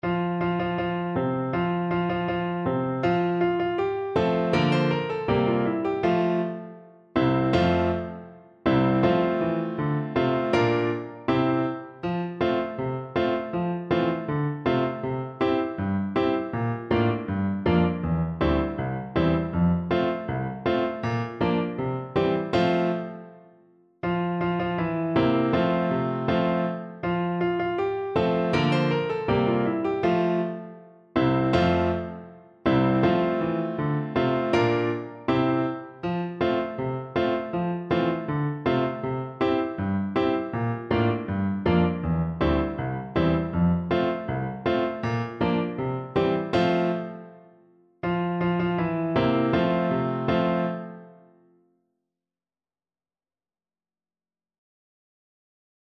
Two in a bar =c.80
2/2 (View more 2/2 Music)
C5-C6
Traditional (View more Traditional Voice Music)
world (View more world Voice Music)